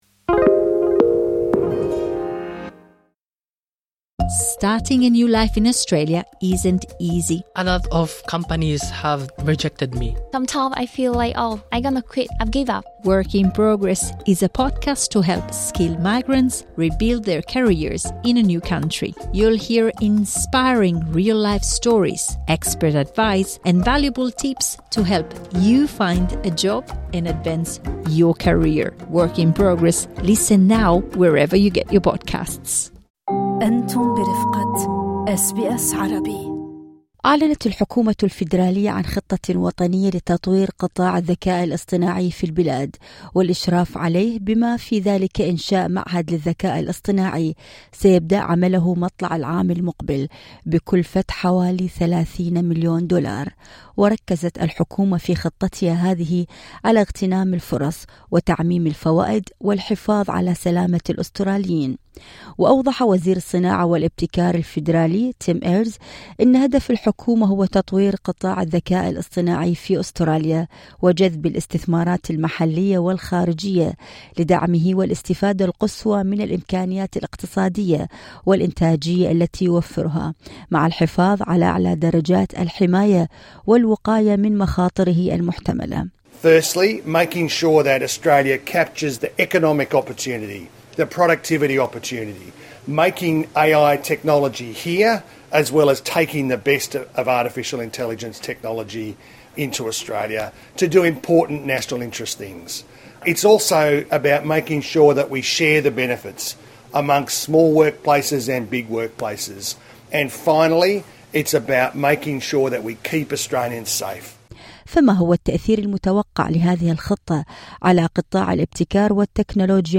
التفاصيل في اللقاء الصوتي اعلاه